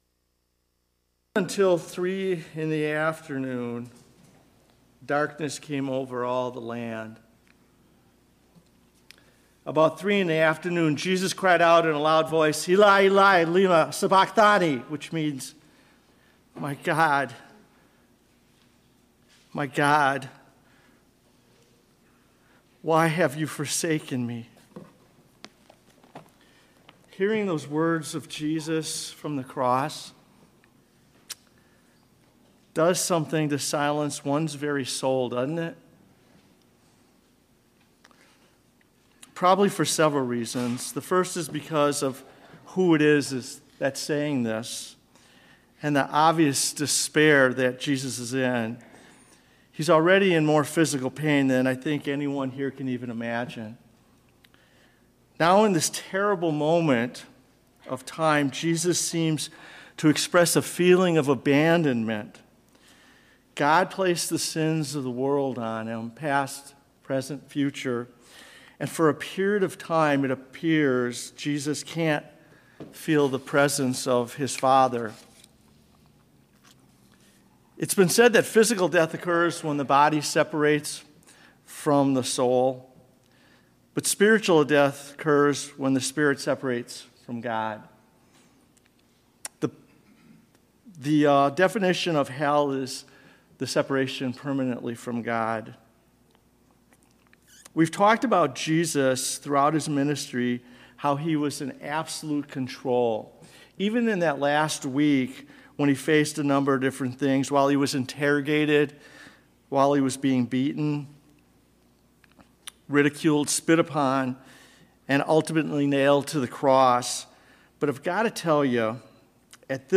Sermon-Audio-February-4-2024.mp3